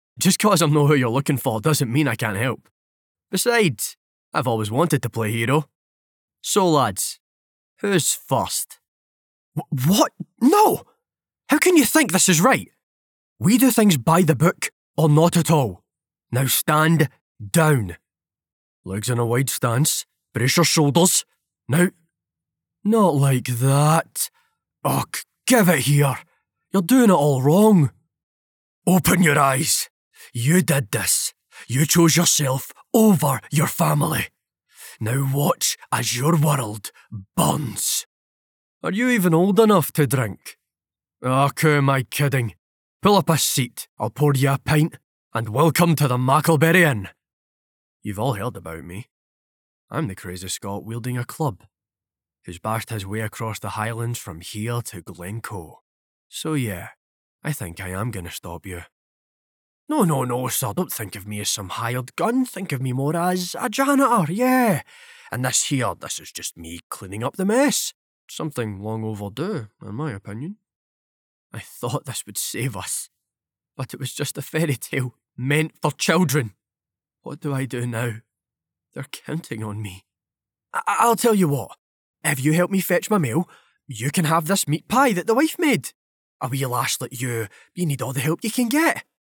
Male
My accent is a Scottish central belt, Glaswegian accent, though clear and informative
Character / Cartoon
Various Scottish Voicelines-
All our voice actors have professional broadcast quality recording studios.
0706Character_Audrioreel_-_Scottish.mp3